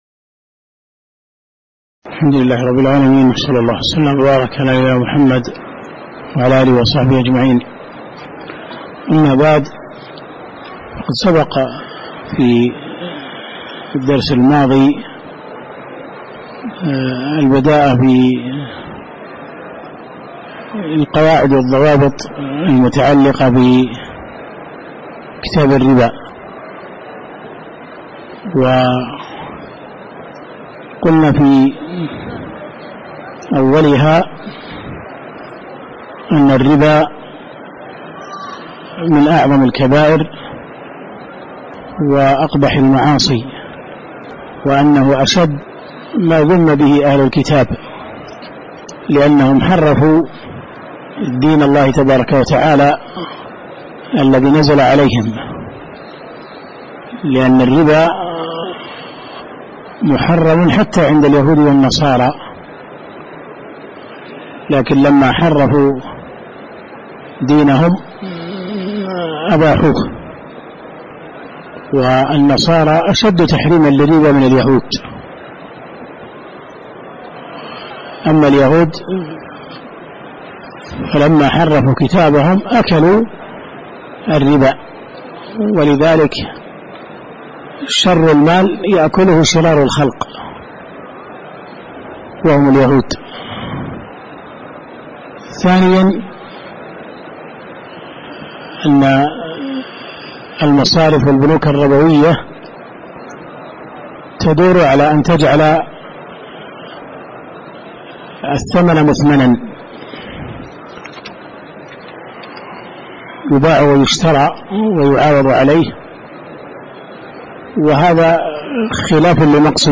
تاريخ النشر ١ ربيع الأول ١٤٣٩ هـ المكان: المسجد النبوي الشيخ